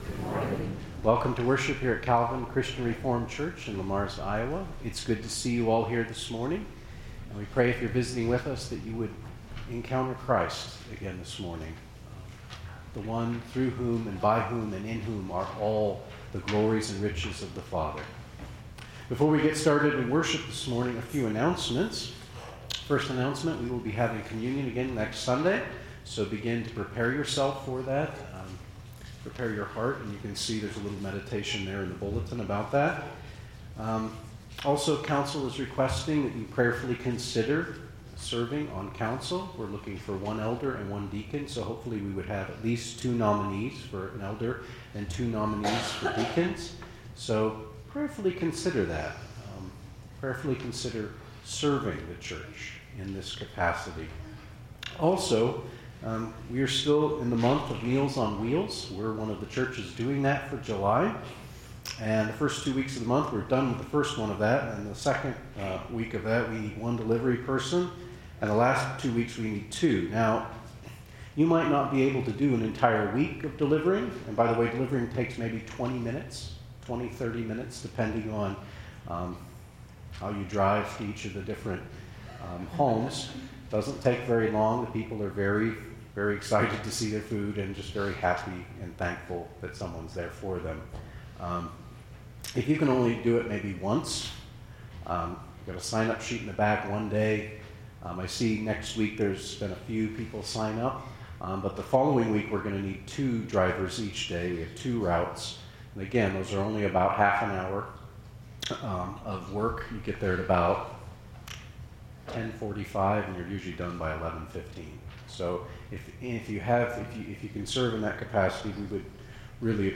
Calvin Christian Reformed Church Sermons